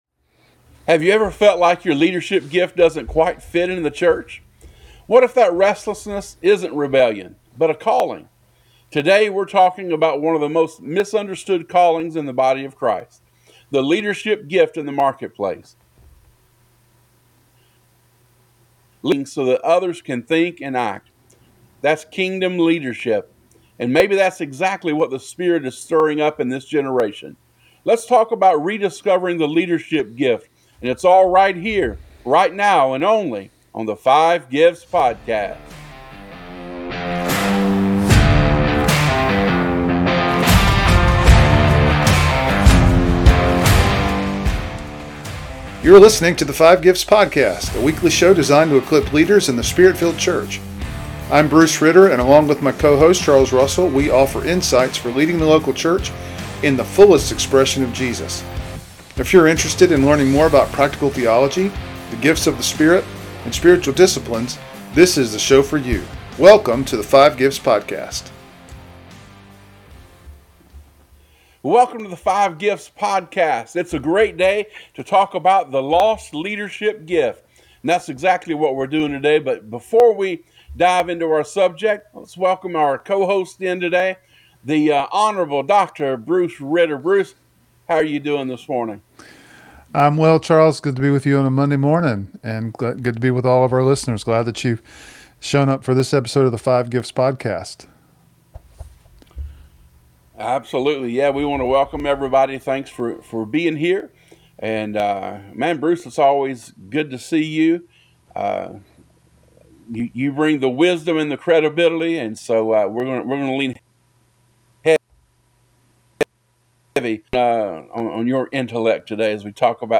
Whether you lead a business, a ministry, or a team, this conversation will remind you that your leadership is not secular – it’s sacred.